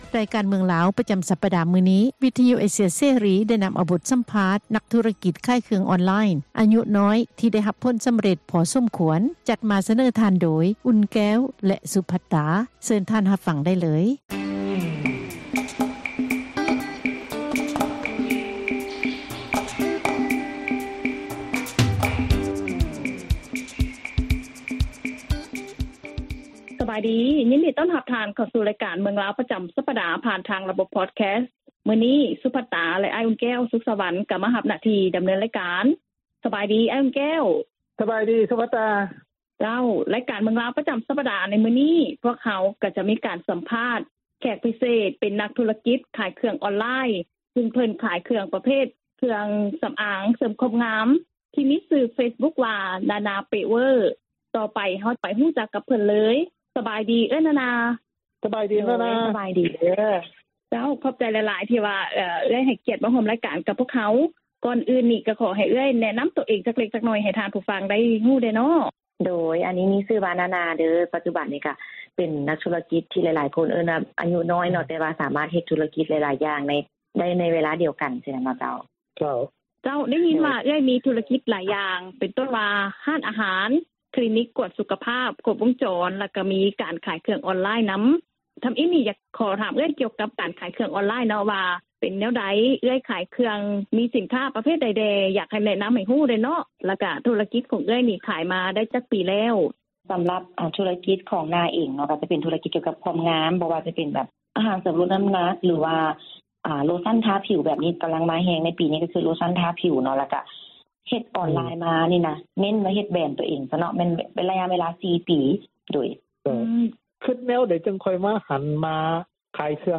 ບົດສຳພາດ ນັກທຸລະກິດ ຂາຍເຄື່ອງອອນລາຍ